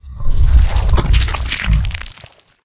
flesh_eat_5.ogg